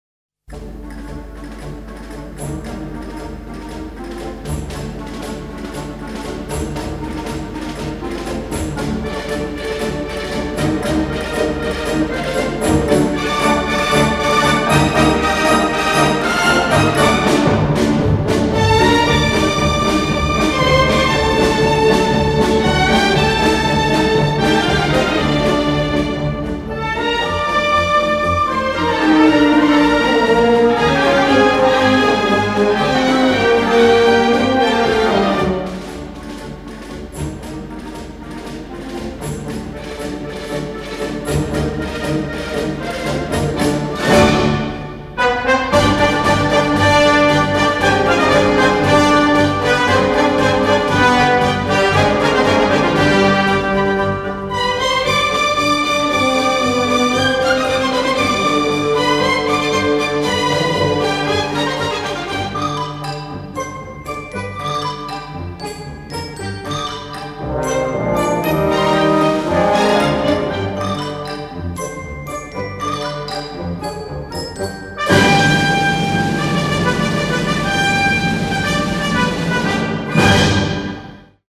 Paso Doble
Elementul de legatura intre corida si muzica acestui dans, este dat mai ales de caracteristica muzicii de mars folosita la inceputul coridei.
paso-doble.mp3